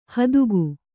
♪ hadugu